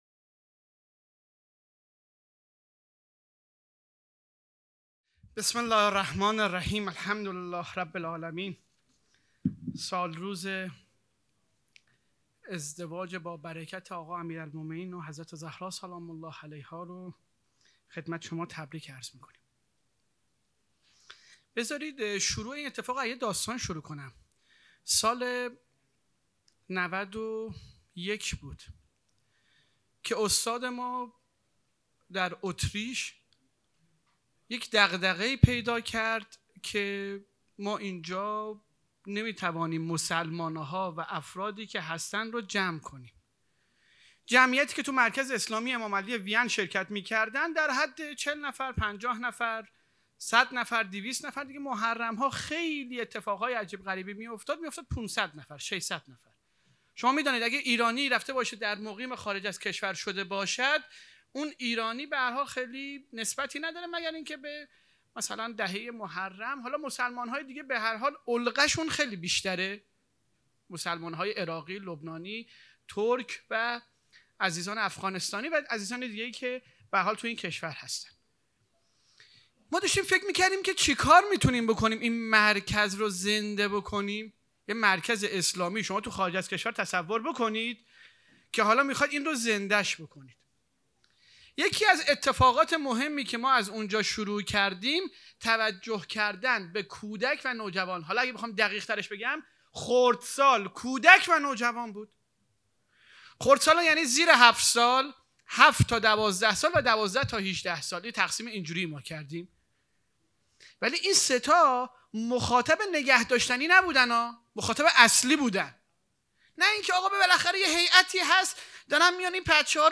پانزدهمین همایش هیأت‌های محوری و برگزیده کشور